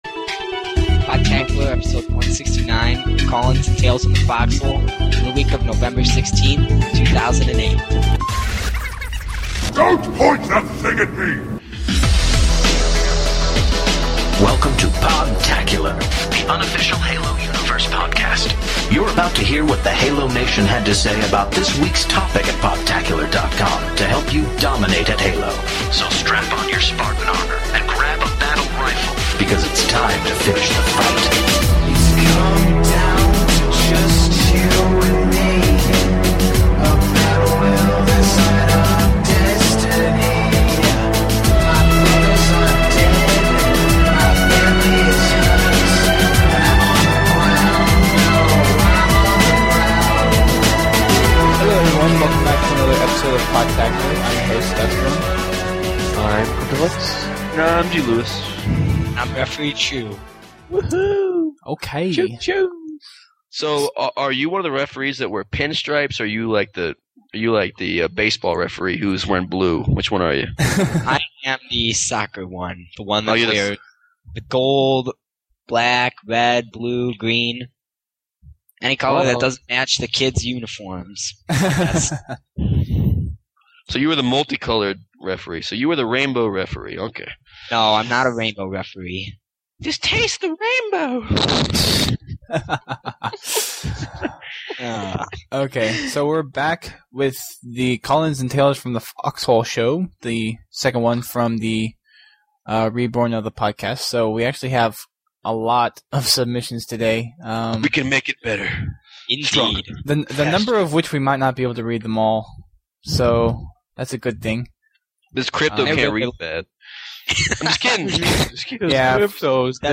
callins